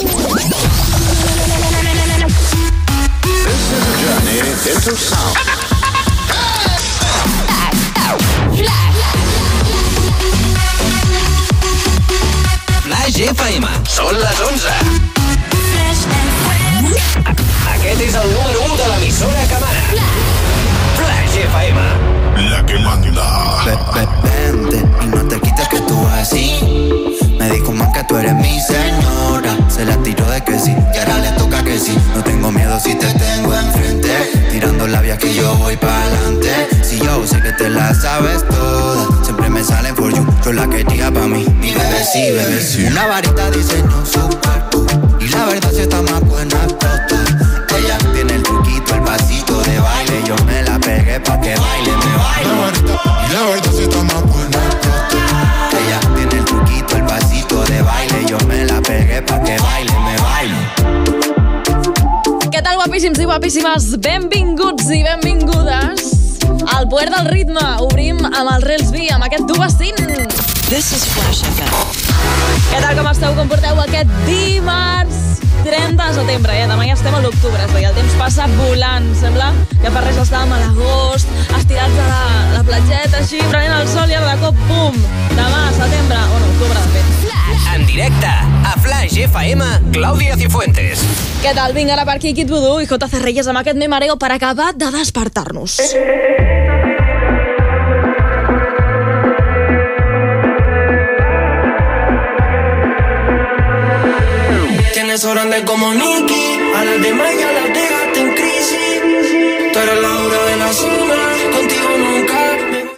Indicatiu de l'emissora, el tema númeo 1. Salutació, data, indicatiu i tema musical.
Musical